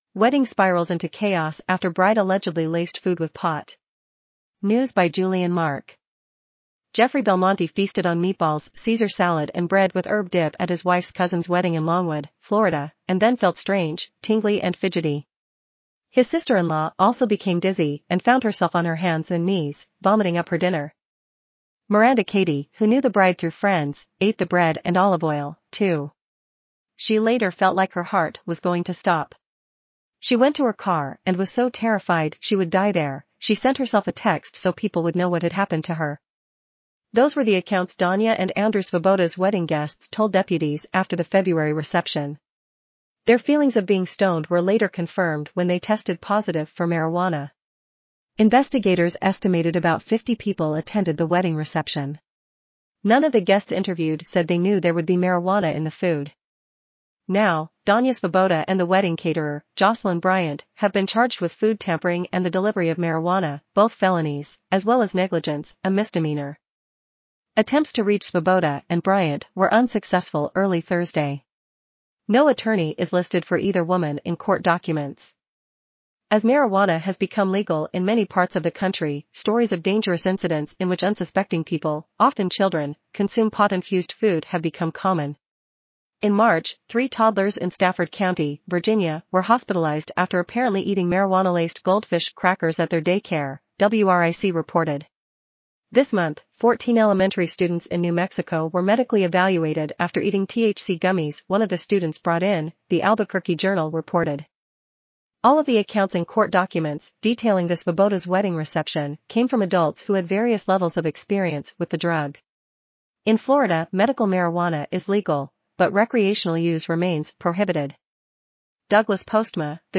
azure_en-US_en-US-JennyNeural_standard_audio.mp3